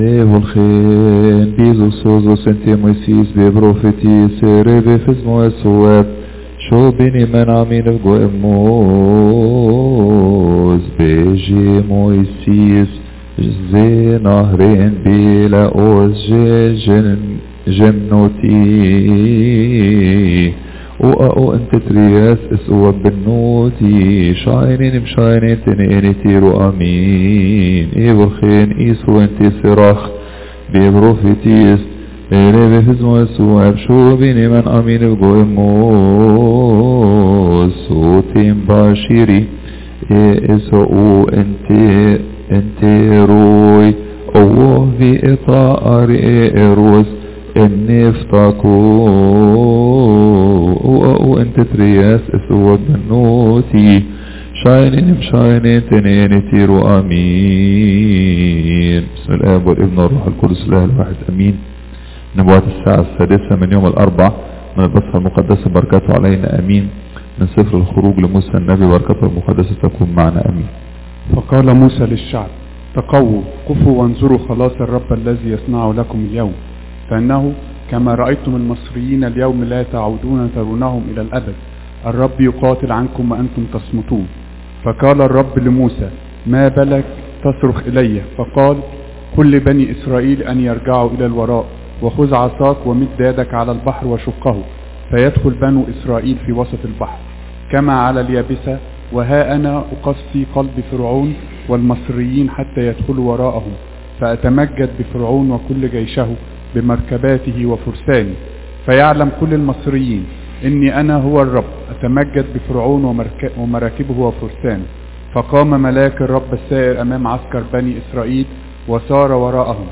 Artist: St. Mary Church Priests & Deacons
Content Type: Service
Mono